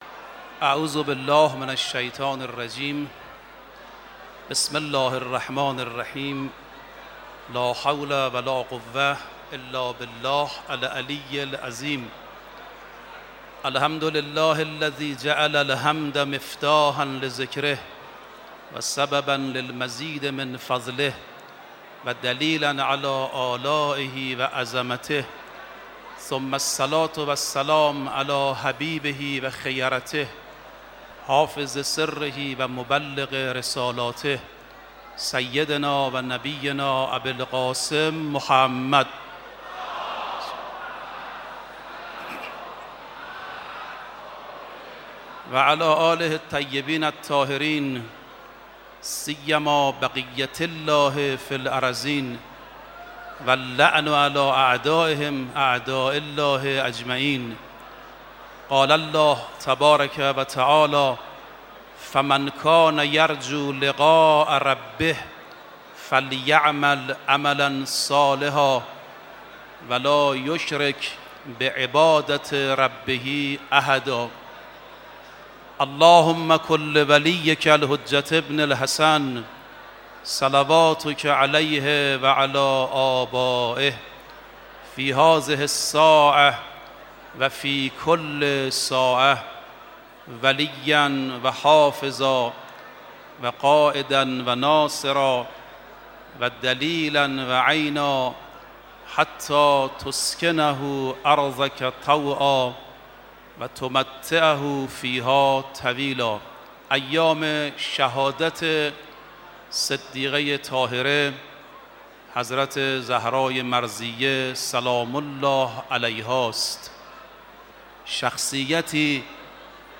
دومین شب عزاداری فاطمیه ۱۴۳۸ در حسینیه امام خمینی
مداحی
سخنرانی